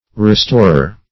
Restorer \Re*stor"er\, n.